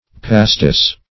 pastis \pastis\ (p[.a]*st[=e]s")